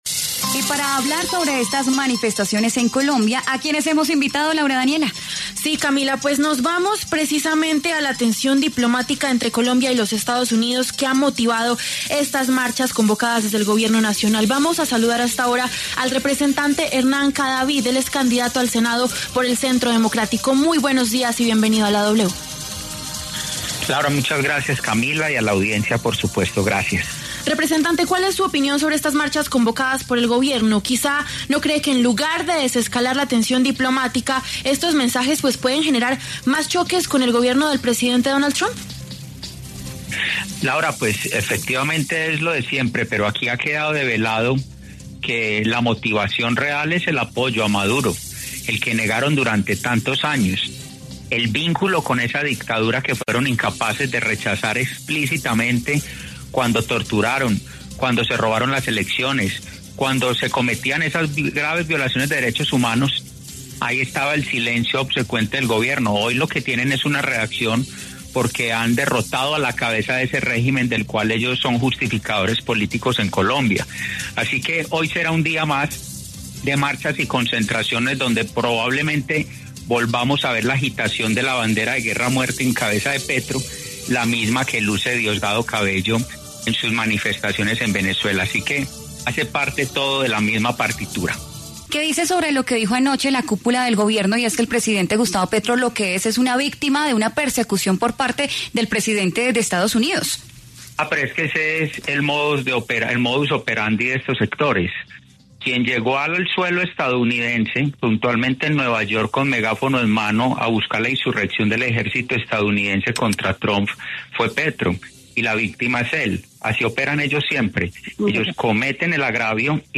Los congresistas Hernán Cadavid, del Centro Democrático, y Esmeralda Hernández, del Pacto Histórico, pasaron por los micrófonos de La W.